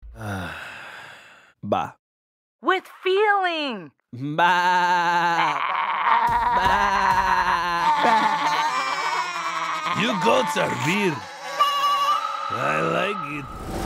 Uhh-Baa-With-Feeling-Cartoon-GOAT-.mp3